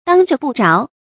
當著不著 注音： ㄉㄤ ㄓㄨㄛˊ ㄅㄨˋ ㄓㄠˊ 讀音讀法： 意思解釋： 指應該做的事不做，而不該做的事卻做了。